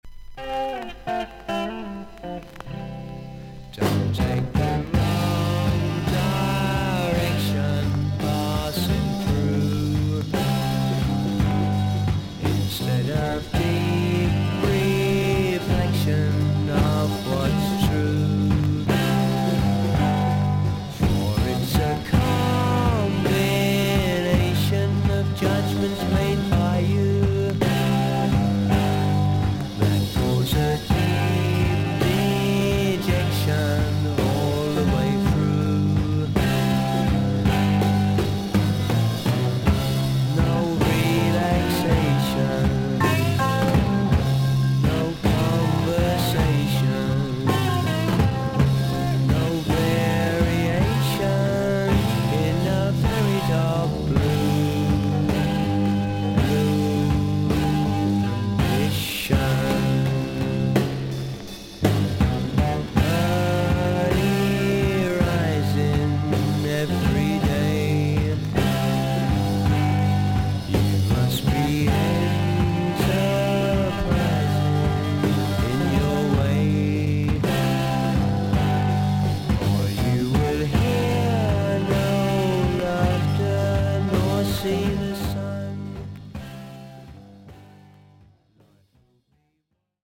それほどノイズに出ません。A5序盤に斜めに8mmのキズ、少々薄く周回ノイズがあります。
少々サーフィス・ノイズあり。クリアな音です。